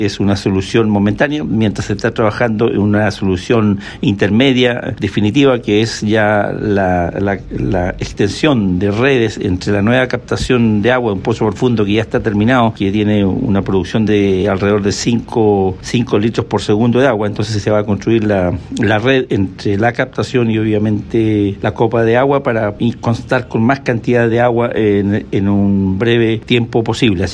El gobernador de Chiloé, Pedro Andrade, expresó que se pensó en la forma más rápida de ayudar a la comunidad.